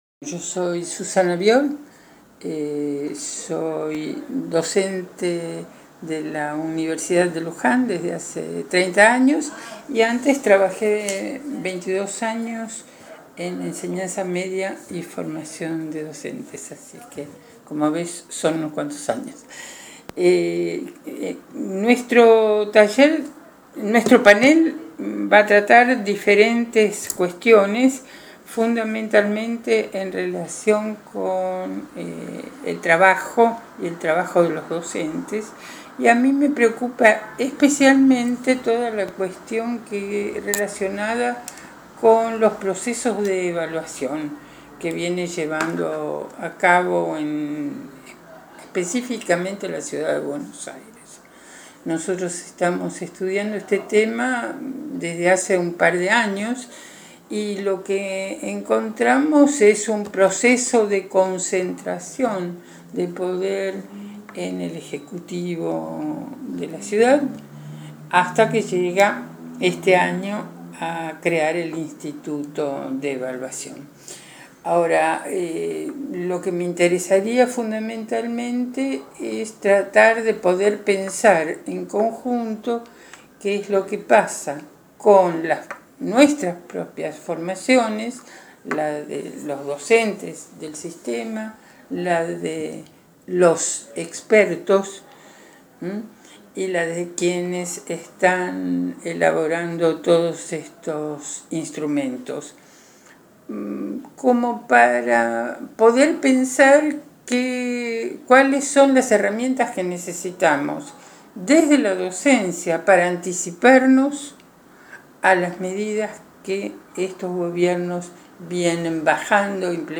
La Izquierda Diario entrevistó a la especialista en educación, que será panelista en el congreso que se hará este fin de semana en el colegio Mariano Acosta de la Ciudad de Buenos Aires.